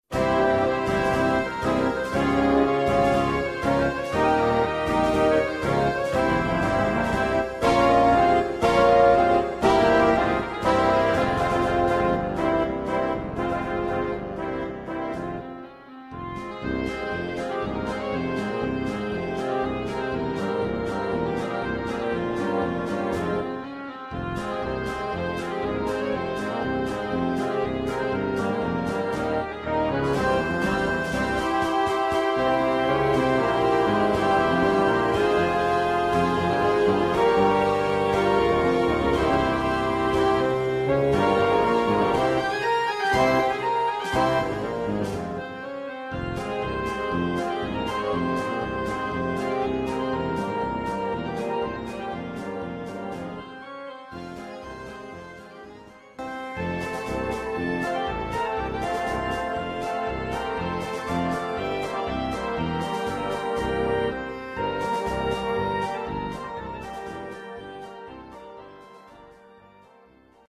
Collection : Harmonie (Feria)
Paso doble pour harmonie
ou fanfare.